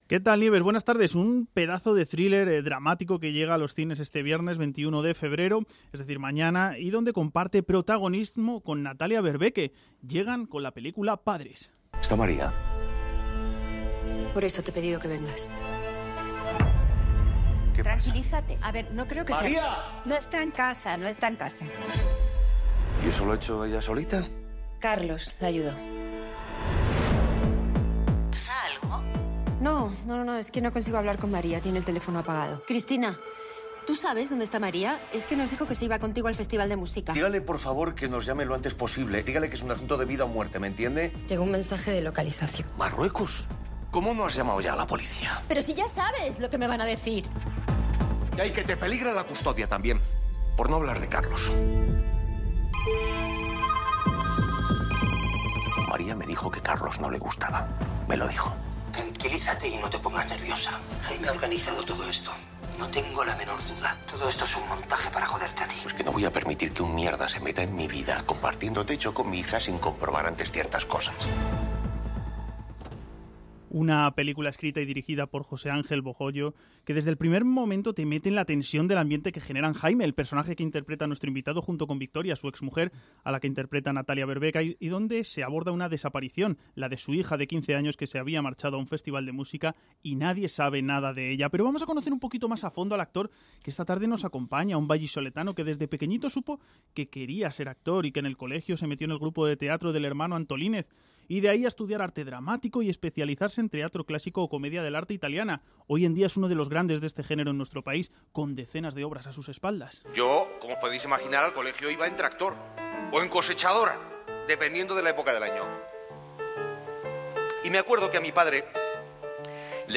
Nieves Herrero se pone al frente de un equipo de periodistas y colaboradores para tomarle el pulso a las tardes.
La primera hora está dedicada al análisis de la actualidad en clave de tertulia.